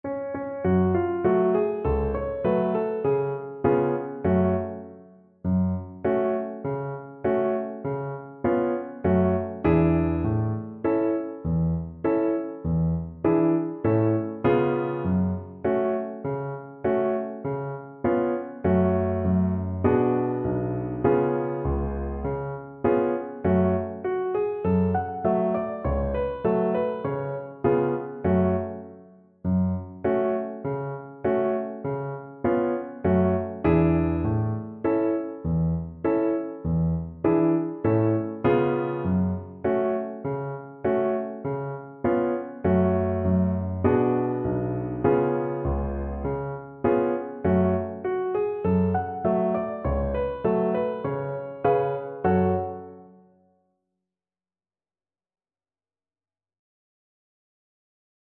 Violin
4/4 (View more 4/4 Music)
Moderato
F# minor (Sounding Pitch) (View more F# minor Music for Violin )
Traditional (View more Traditional Violin Music)